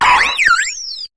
Cri de Shaymin dans sa forme Céleste dans Pokémon HOME.
Cri_0492_Céleste_HOME.ogg